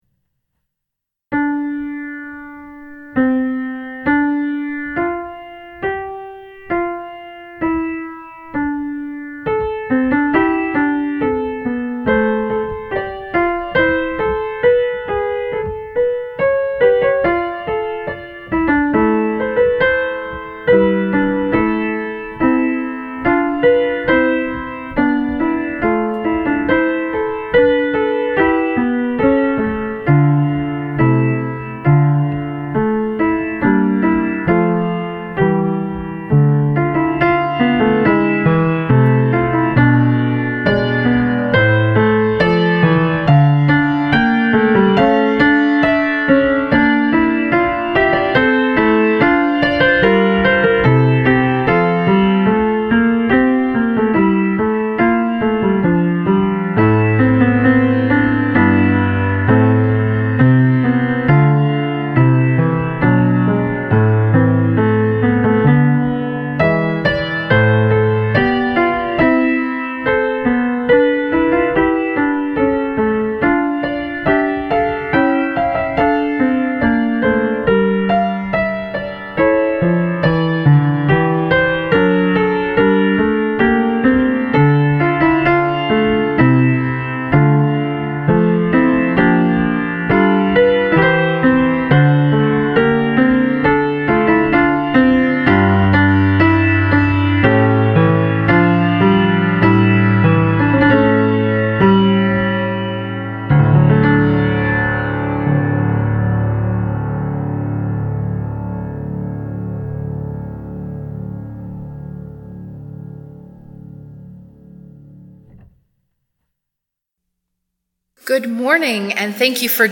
St. James Westminster, London, Canada Church Services
The Great Vigil of Easter for April 3rd